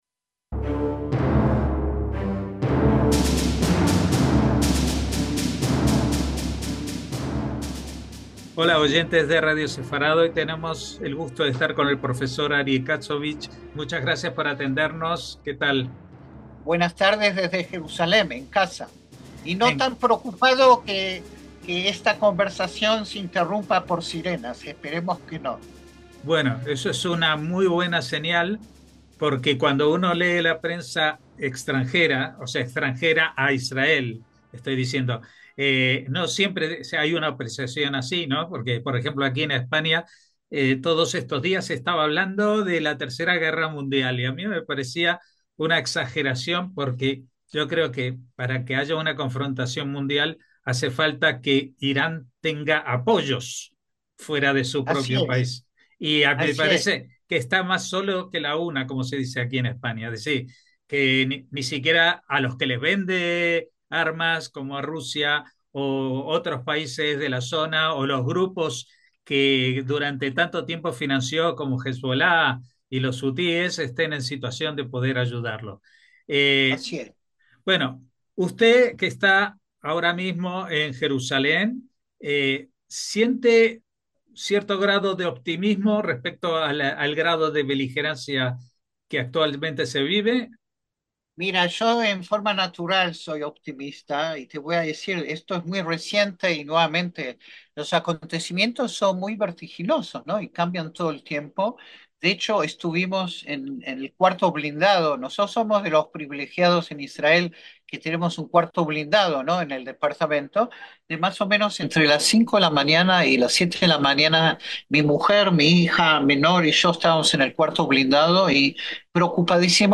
Hablamos con él apenas unas horas después de iniciarse un alto el fuego "accidentado" entre Israel e Irán, pero que tras las reprimendas del presidente estadounidense parace consolidarse.